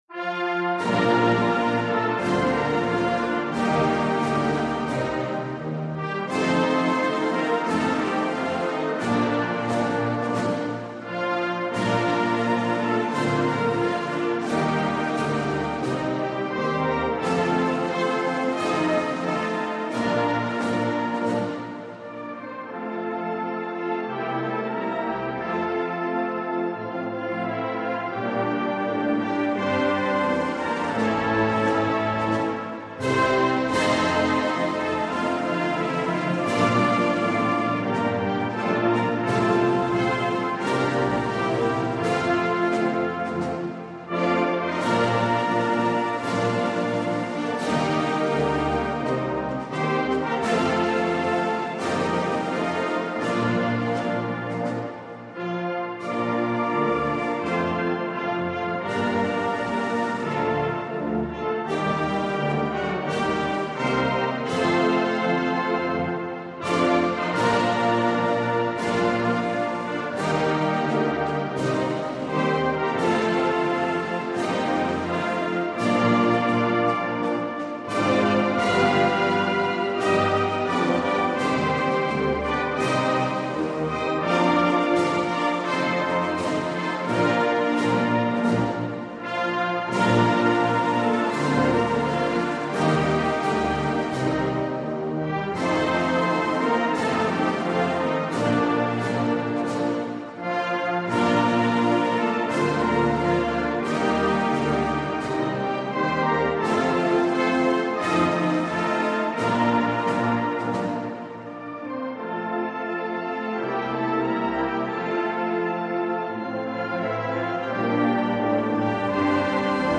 без слов